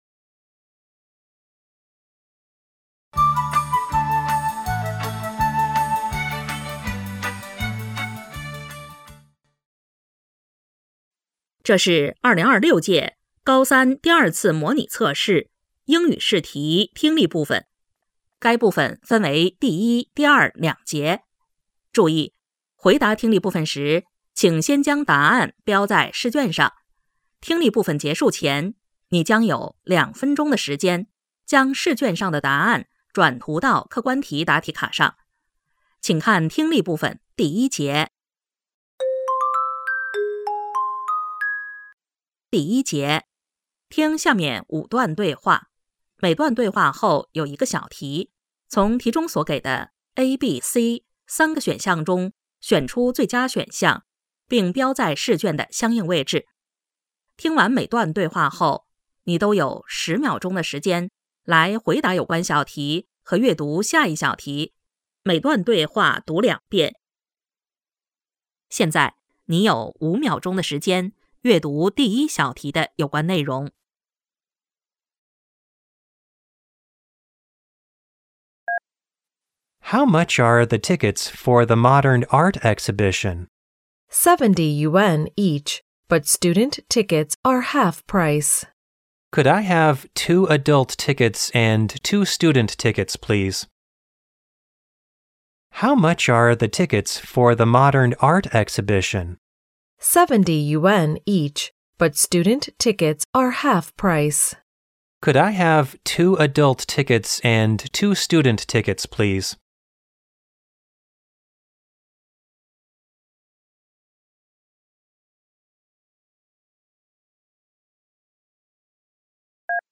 2026届眉山二诊英语听力.mp3